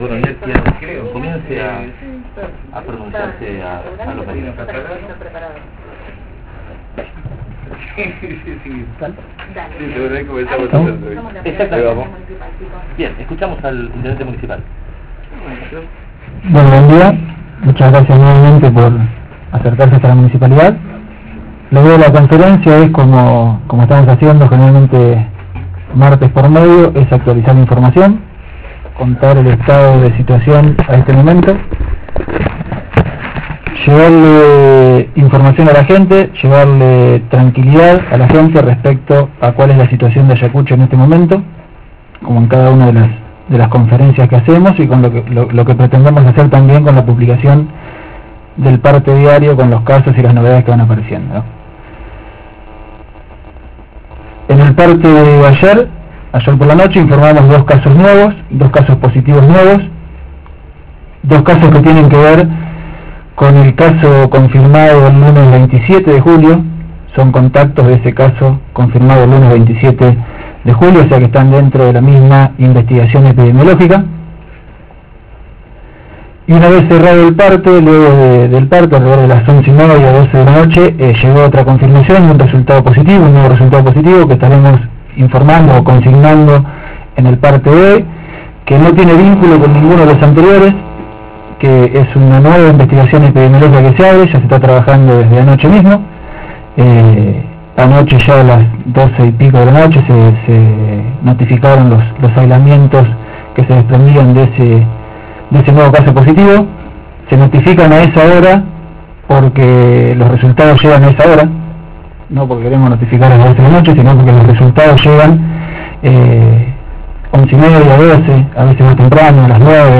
Este martes a las 10 el Intendente Municipal Lic. Emilio Cordonnier brindó una nueva conferencia de prensa, para dar a conocer la situación en Ayacucho sobre la evolución de la pandemia. Hay 21 casos confirmados, y la ciudad se mantiene en fase 4, con ciertas restricciones.